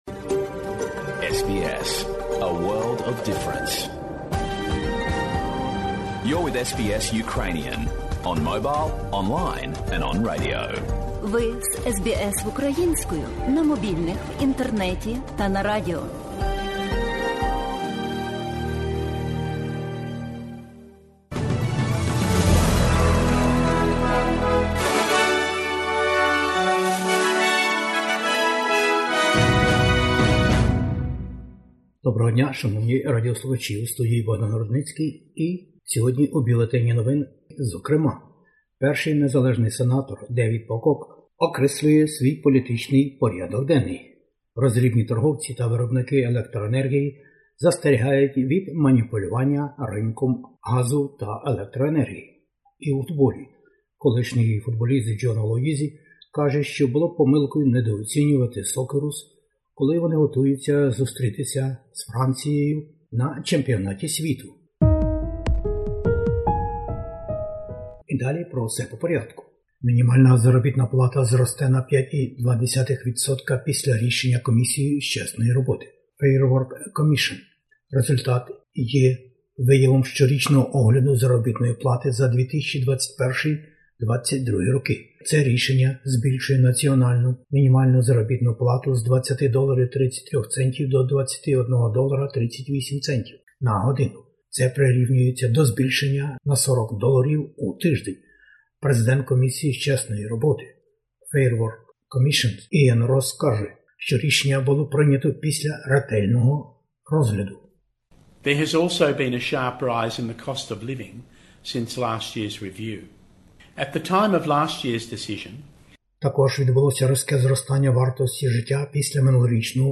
Бюлетень SBS новин - про події в Австралії, Україні та світі. Мінімальну заробітну платню в Австралії буде підвищено - Fair Work Commission.